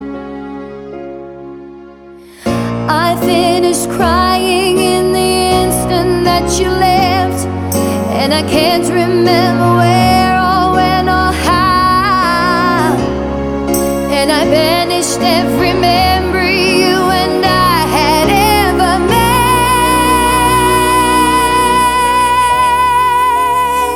Male Solo Version Rock 4:14 Buy £1.50